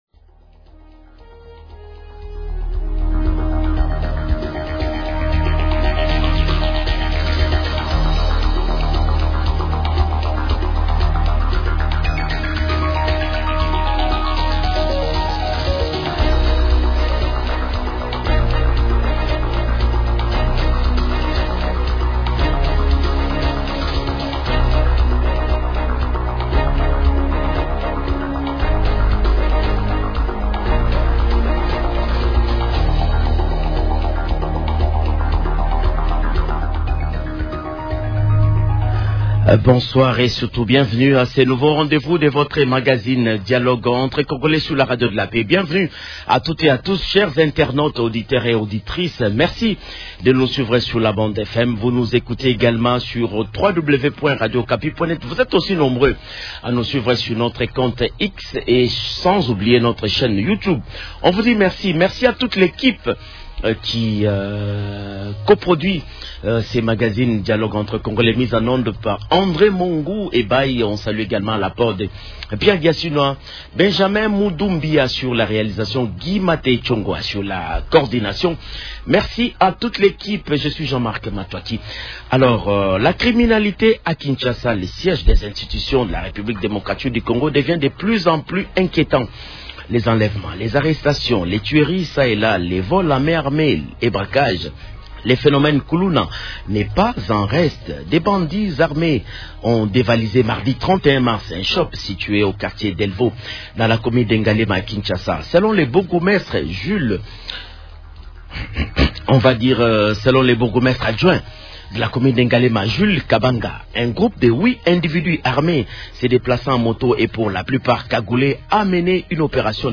-Alphonse Ngoyi Kasanji, sénateur élu de Mbuji Mayi et ancien gouverneur du Kasaï-Oriental.